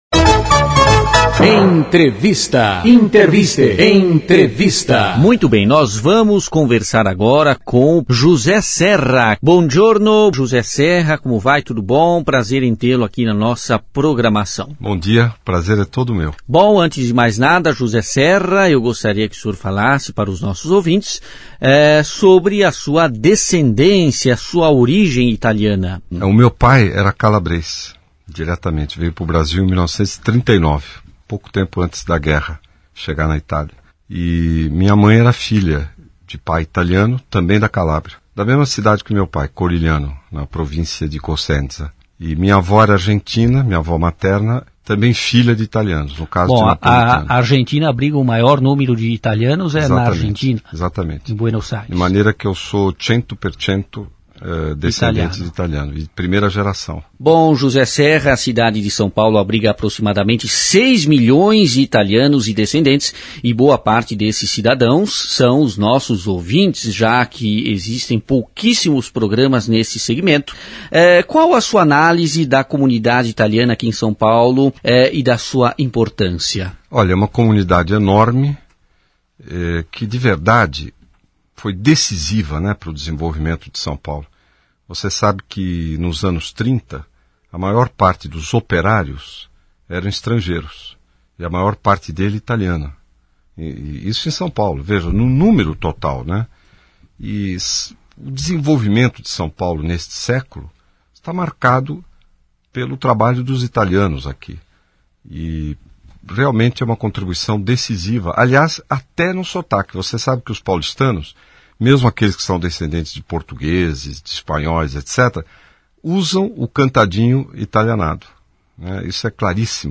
ENTREVISTA: Político ítalo-brasileiro José Serra é entrevistado na Rádio italiana
O ítalo-brasileiro, José Serra é o entrevistado da Rádio Italiana.
Entrevista Serra.mp3